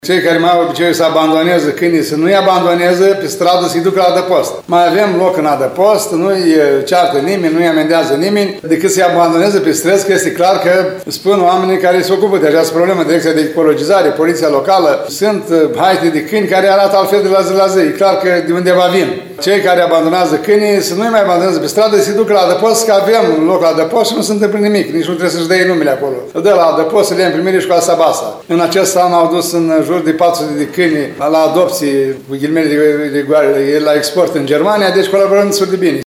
Primarul ION LUNGU a făcut un nou apel către localnici să ducă câinii pe care nu mai doresc să-i crească în adăpostul din zona Termica.